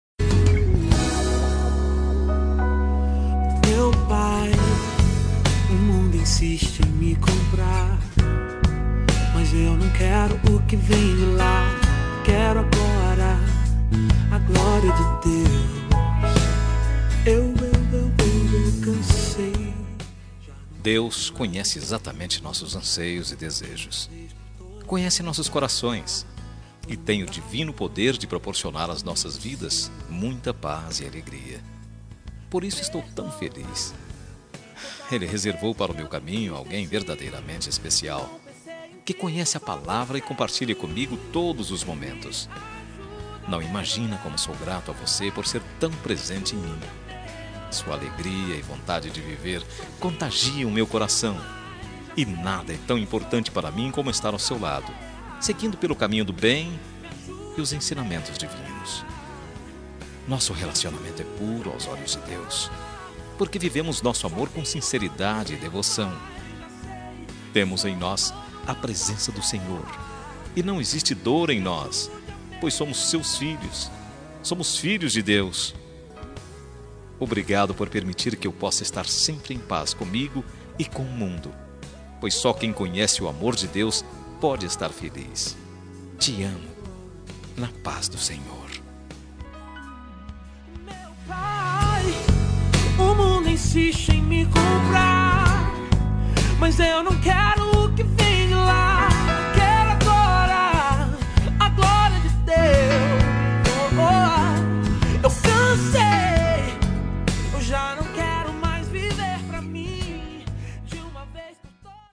Telemensagem Romântica Gospel – Voz Masculina – Cód: 5246